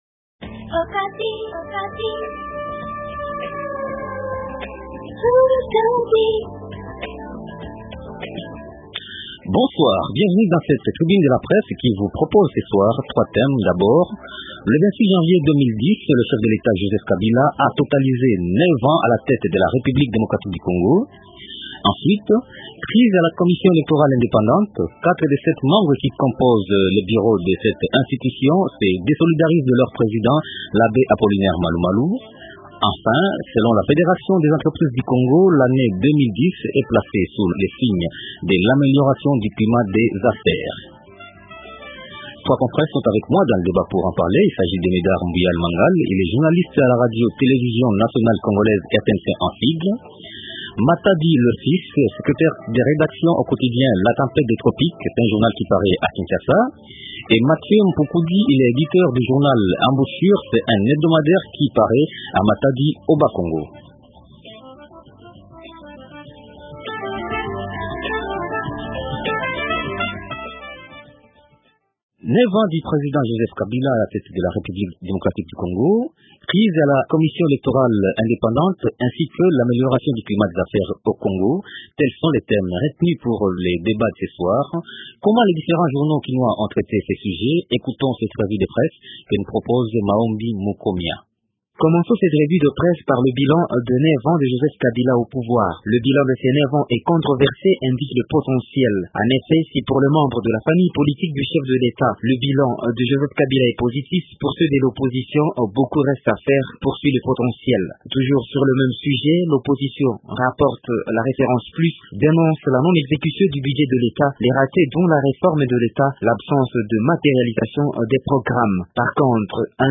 Trois thèmes dans la tribune de presse de ce soir :